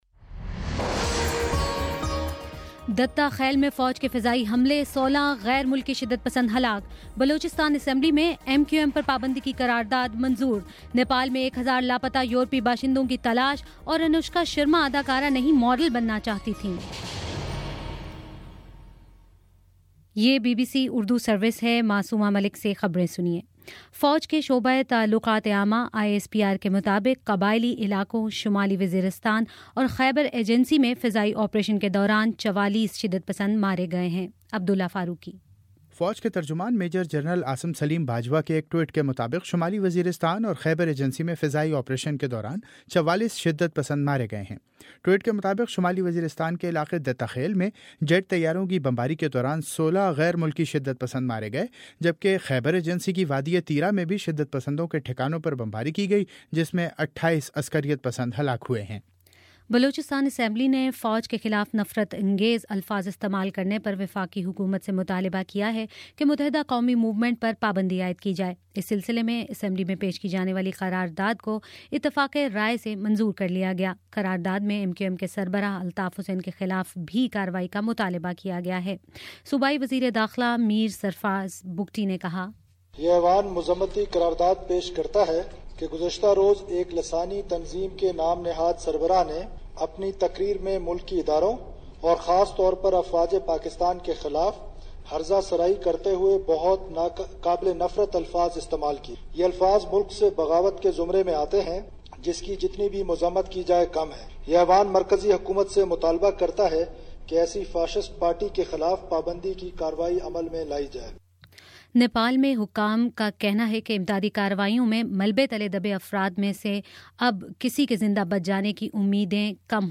مئی 02 : شام سات بجے کا نیوز بُلیٹن